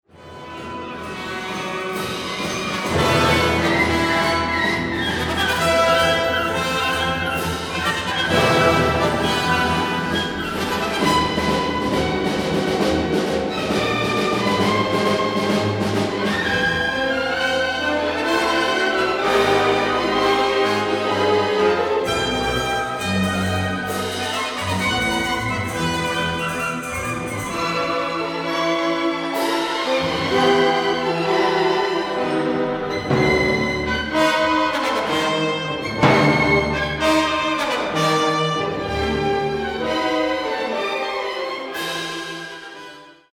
W zakończeniu Kondraszyn umiejętnie eksponuje ostrą wyrazistą rytmikę: